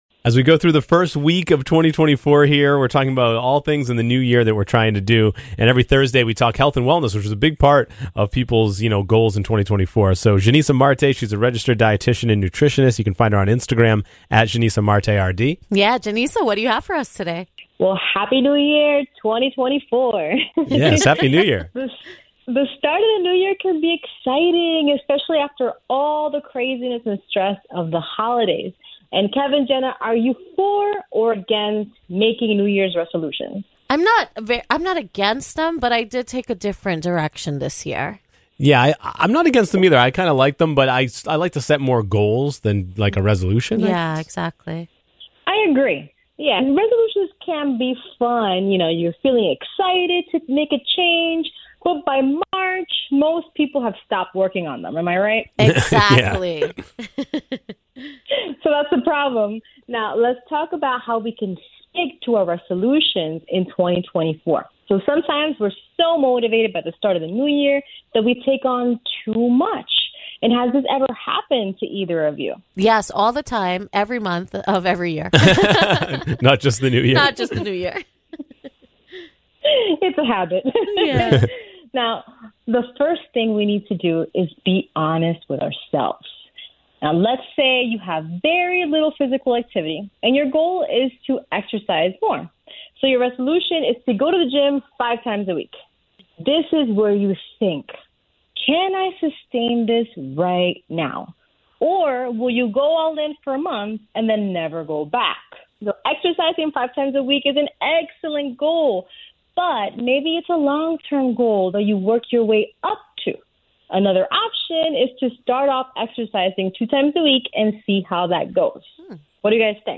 chat with an expert about all things health and wellness